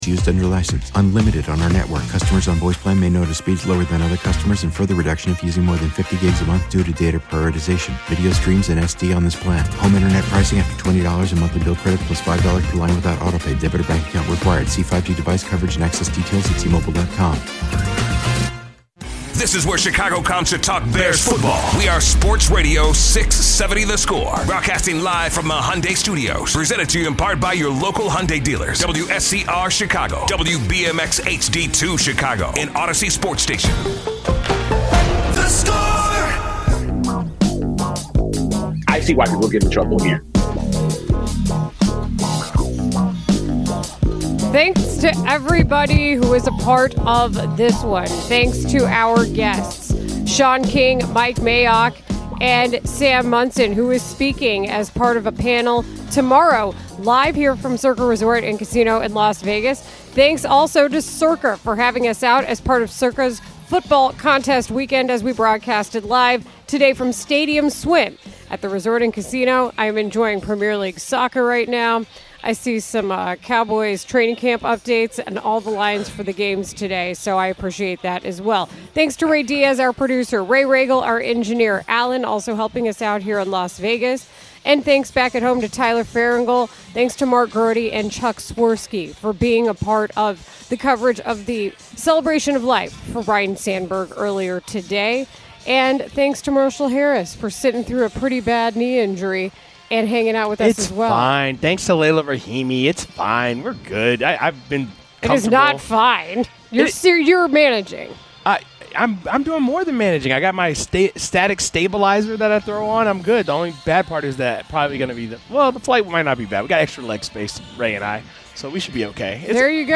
live from Stadium Swim at Circa Las Vegas